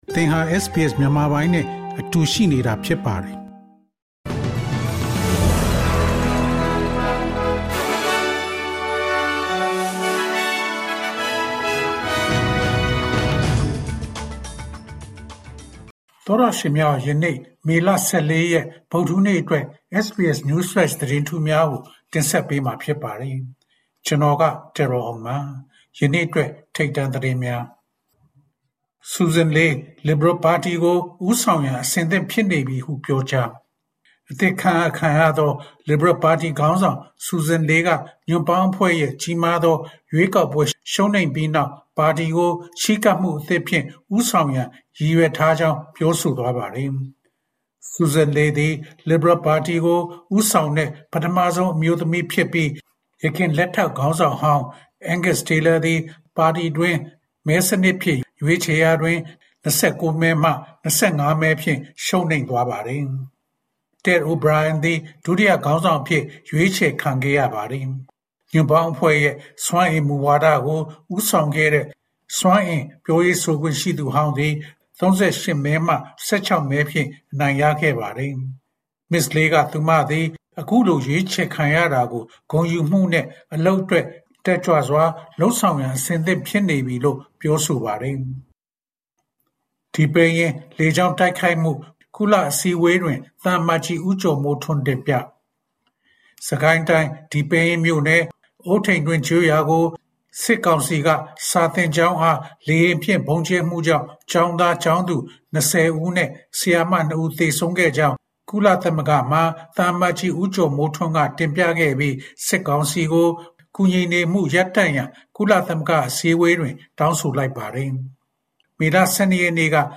SBS Burmese News Flash Source: SBS / SBS Burmese